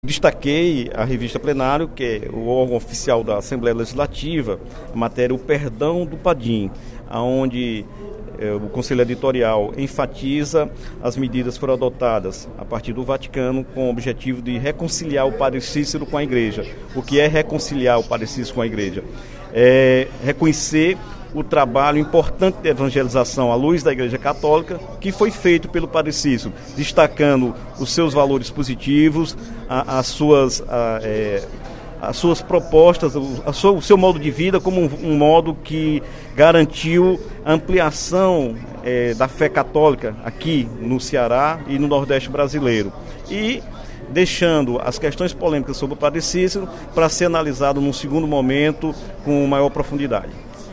O deputado Dr. Santana (PT) parabenizou, no primeiro expediente da sessão plenária desta terça-feira (10/05), os editores da Revista Plenário, da Coordenadoria de Comunicação Social da Assembleia Legislativa, pela matéria “O Perdão do Padim”, publicada na 43° edição que circula desde a última segunda-feira. O parlamentar também destacou, em seu pronunciamento, os investimentos anunciados pelo governador do Estado, Camilo Santana, para a área da educação.